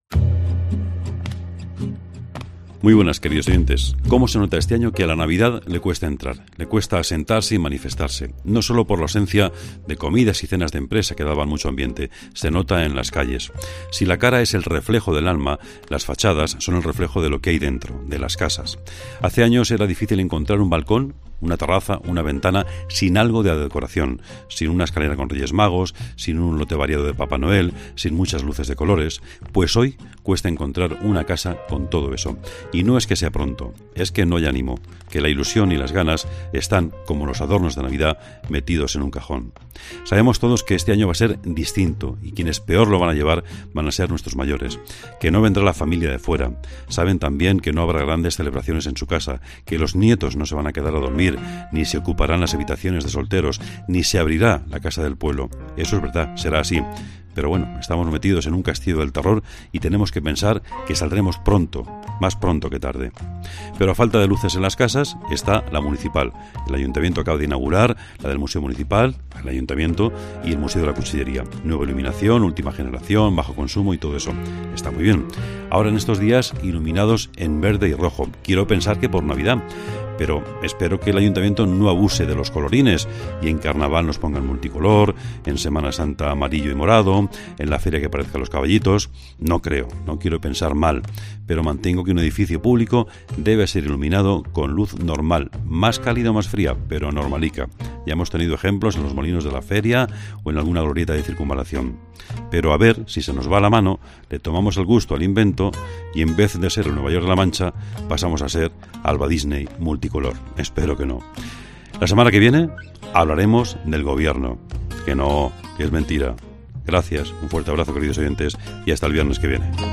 OPINIÓN